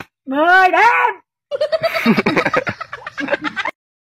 Tải âm thanh "10 điểm" - Hiệu ứng âm thanh chỉnh sửa video